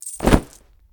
hangingClothe.wav